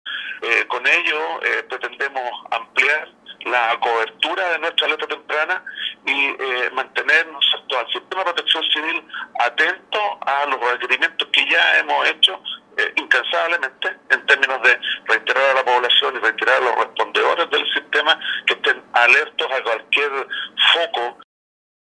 Alejandro Vergés, director regional de Onemi, explicó el motivo de la ampliación de la alerta temprana preventiva para toda la región de Los Lagos.